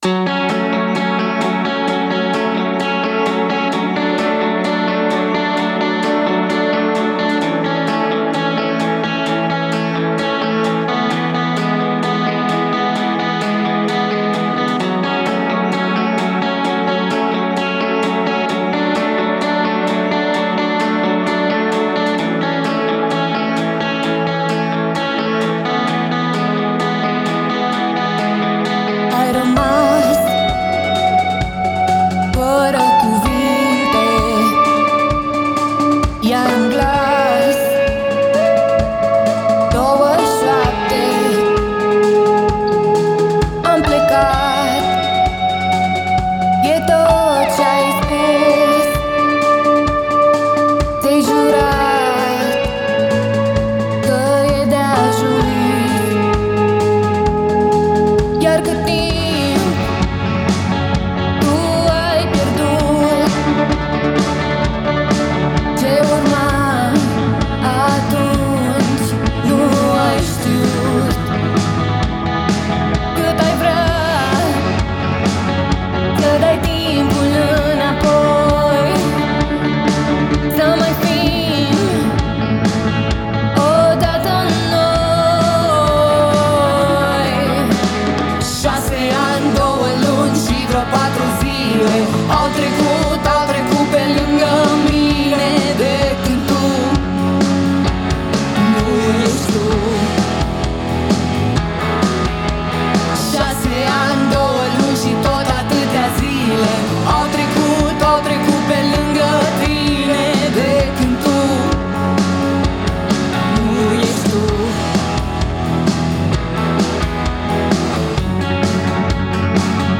indie-rock-alternativ cu compoziții proprii și originale
voce
clape
bass
chitară si voce